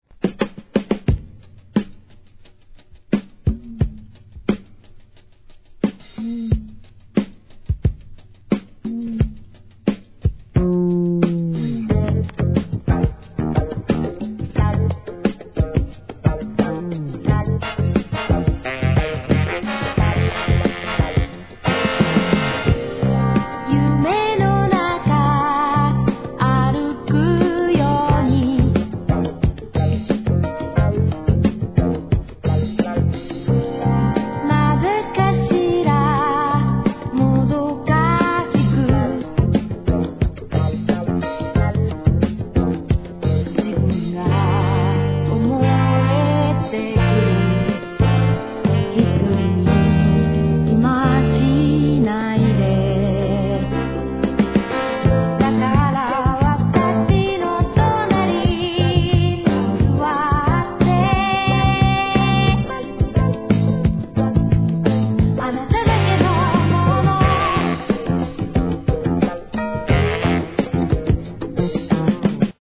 SOUL/FUNK/etc...
ドラム・ブレイク